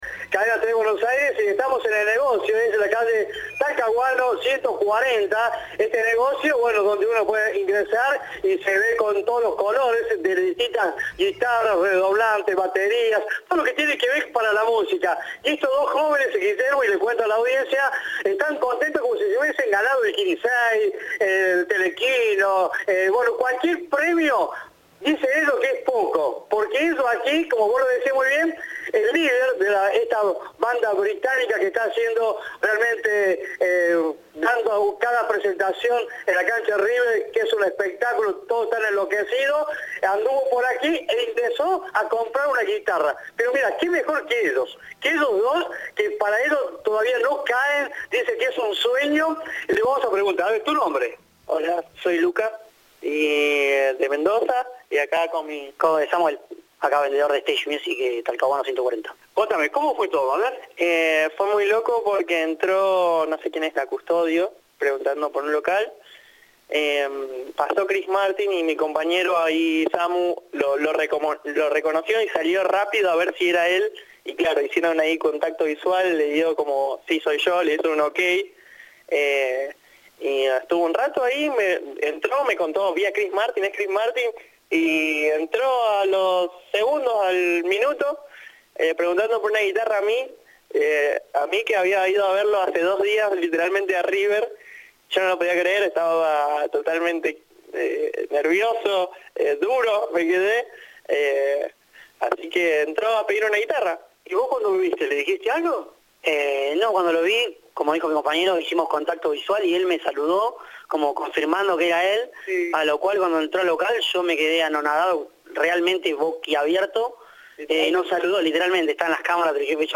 Los encargados contaron a Cadena 3 cómo fue la inolvidable experiencia.
Informe